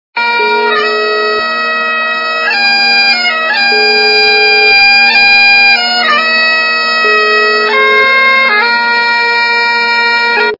» Звуки » звуки для СМС » Инструмент - Irish_bagpipe
При прослушивании Инструмент - Irish_bagpipe качество понижено и присутствуют гудки.
Звук Инструмент - Irish_bagpipe